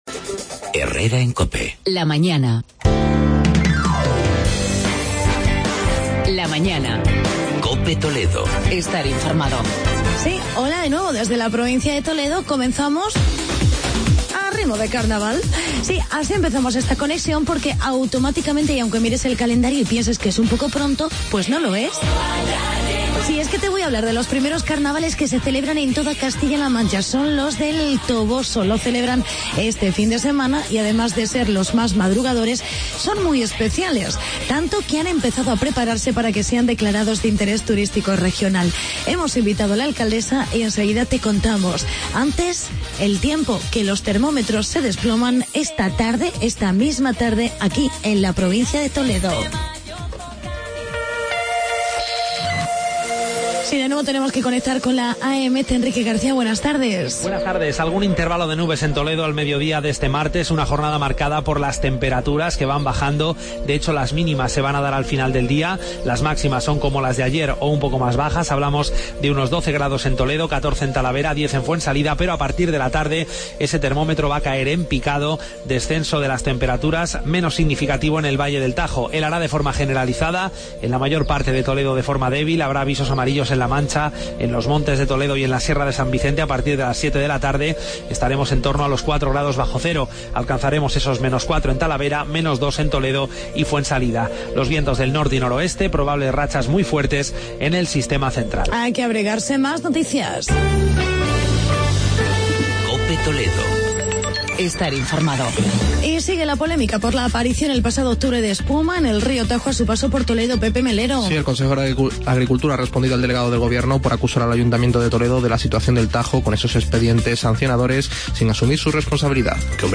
Actualidad y entrevista con Pilar Arinero, alcaldesa de El Toboso por sus Carnavales.